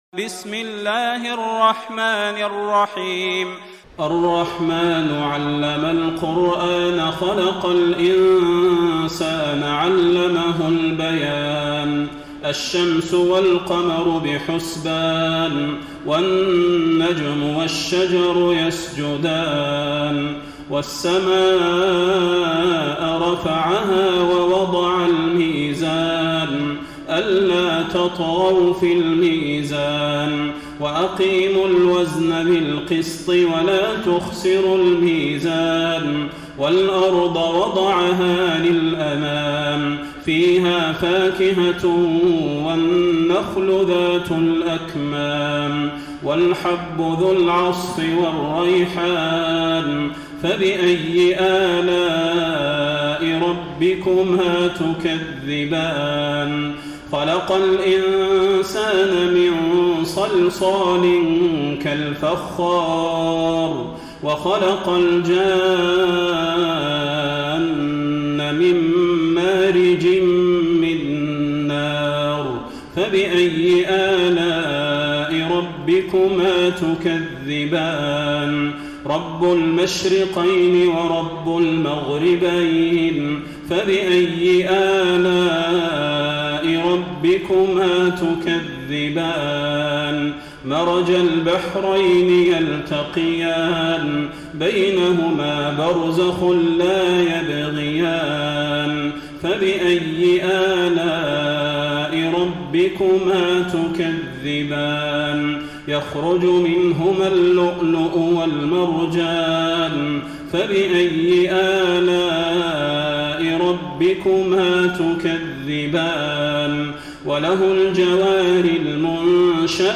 تراويح ليلة 26 رمضان 1433هـ من سور الرحمن الواقعة و الحديد Taraweeh 26 st night Ramadan 1433H from Surah Ar-Rahmaan and Al-Waaqia and Al-Hadid > تراويح الحرم النبوي عام 1433 🕌 > التراويح - تلاوات الحرمين